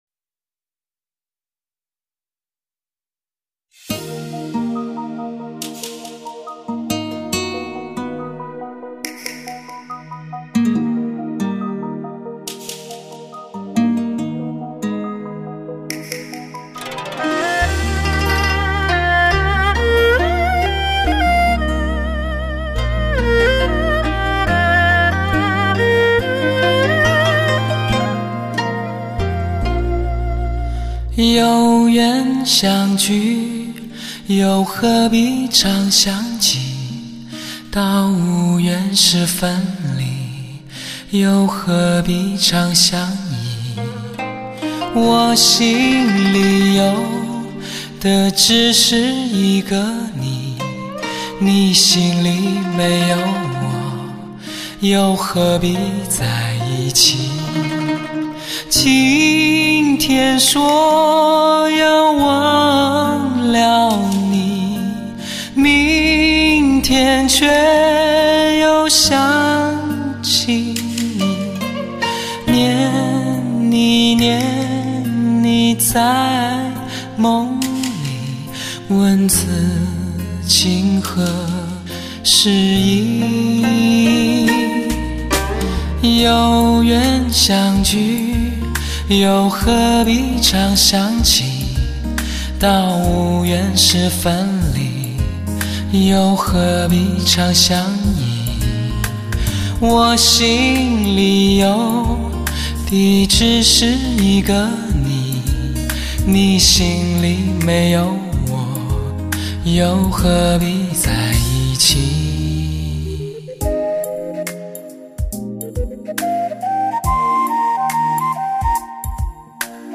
极品音质完美体验
经典流行歌曲重新发烧编曲，歌手演绎亦是至情至圣。
带给你高清解析、震撼音效、完美音质的音乐作品。